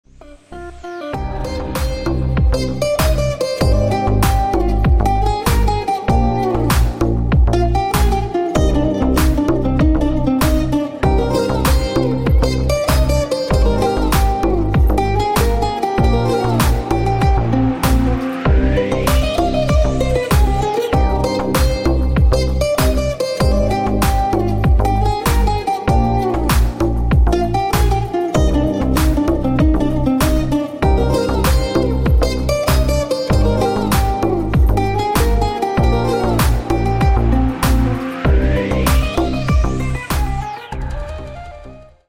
• Качество: 128, Stereo
гитара
спокойные
релакс
восточные
расслабляющие
Стиль: deep house.